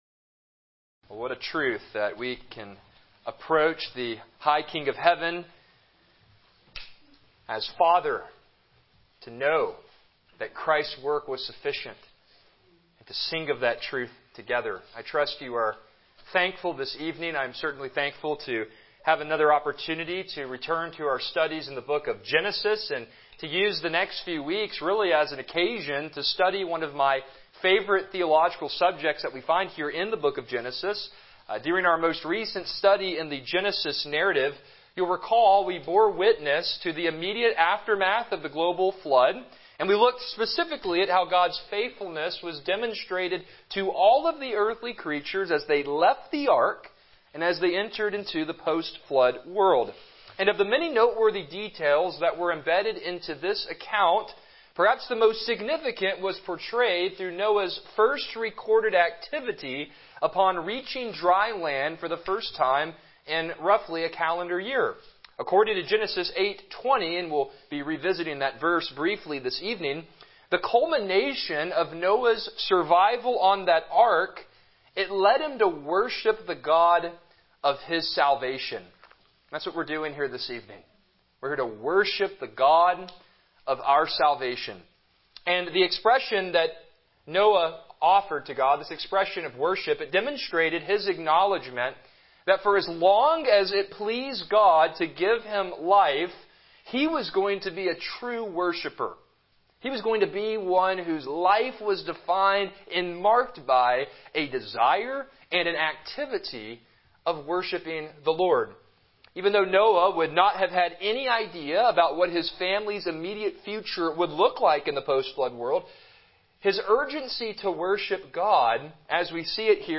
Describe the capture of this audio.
Passage: Genesis 8:20-22 Service Type: Evening Worship